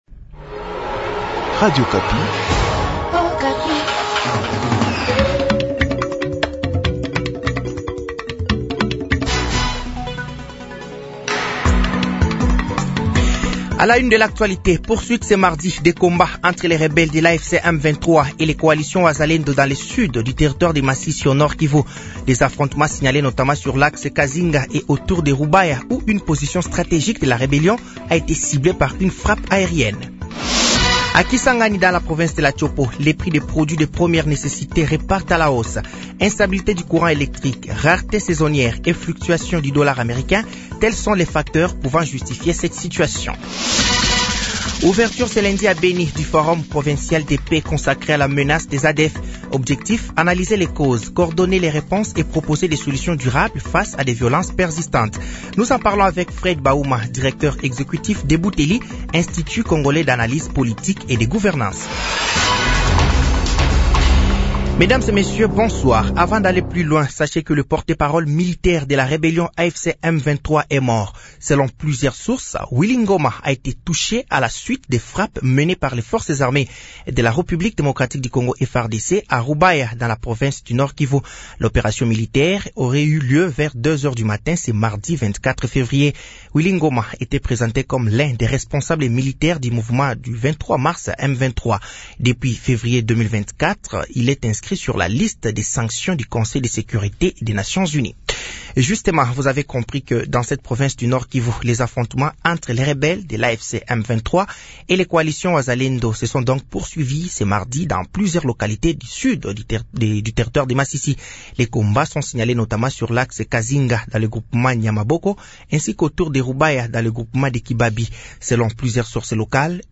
Journal français de 18h de ce mardi 24 février 2026